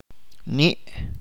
[am.’luʔ] sustantivo generalmente no poseido worm